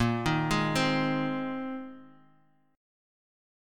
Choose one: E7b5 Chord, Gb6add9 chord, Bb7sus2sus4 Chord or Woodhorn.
Bb7sus2sus4 Chord